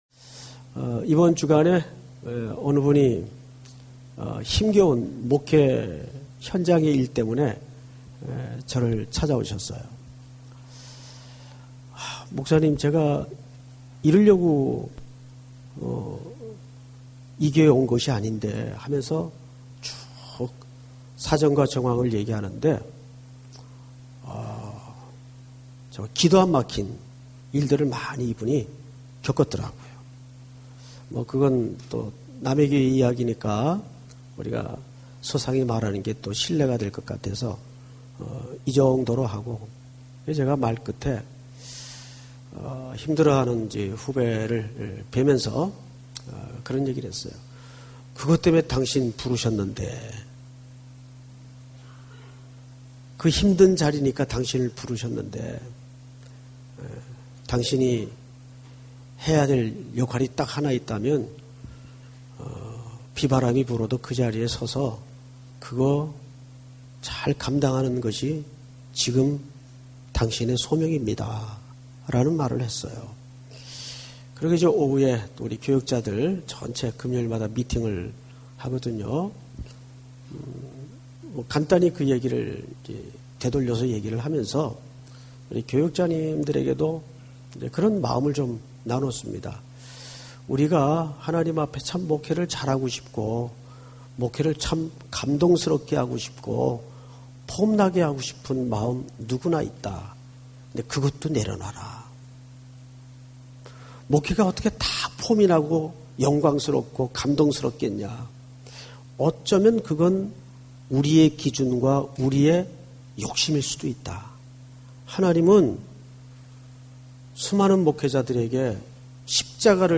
예배 철야예배